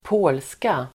Uttal: [²p'å:lska]